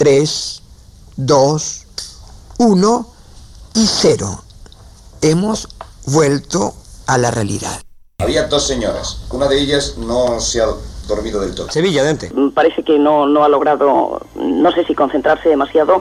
Final de l'experiència i informació des dels estudis de Madrid i Sevilla de com ha anat l'experiència hipnòtica per ràdio.
Informatiu